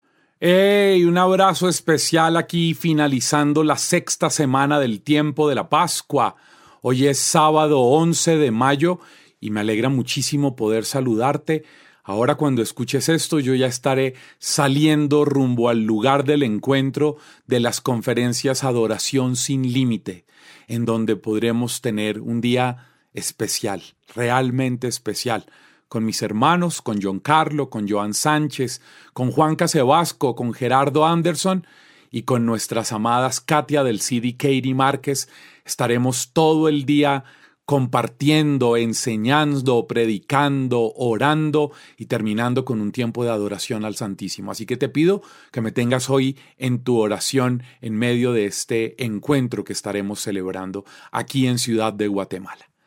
El congreso titulado «Adoración sin Límites» reunió a una multitud ávida de espiritualidad y conexión divina en Parque de la industria.